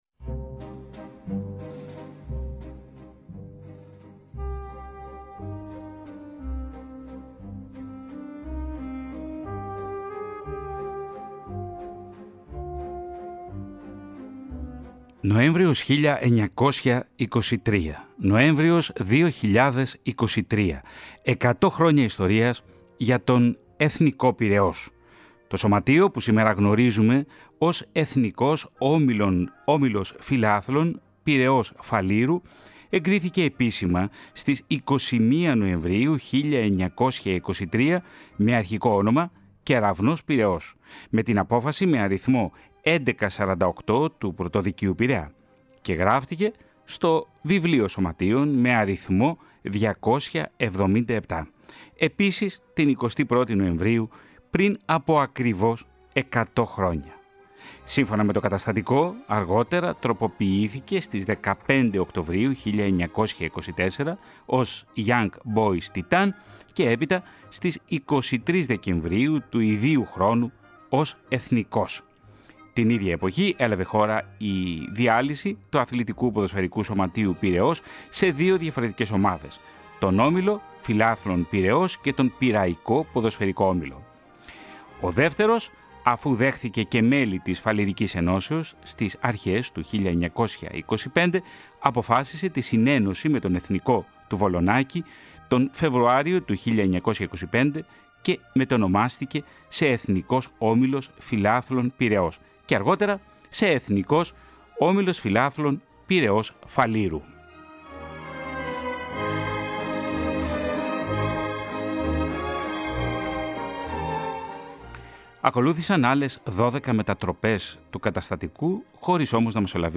αθλητικό ραδιοφωνικό ντοκιμαντέρ